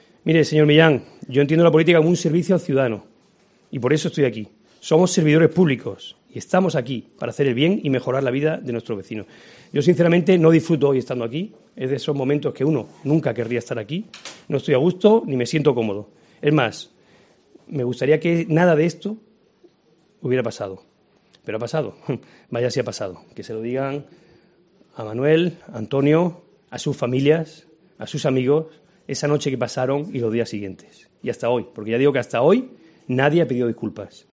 El alcalde de Jaén habla de la "Operación Pandilla"
González, en una intervención de ocho minutos, convocada de urgencia y sin turno de preguntas, ha comenzado su intervención aludiendo a la noche de la jornada de reflexión previa a las pasadas municipales cuando se conoció la noticia de una supuesta compra de votos del PP en Jaén capital.